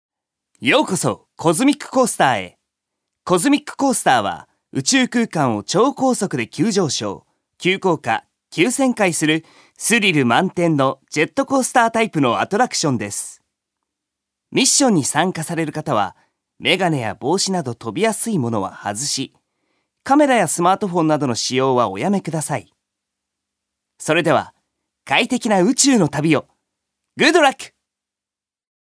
所属：男性タレント
ナレーション２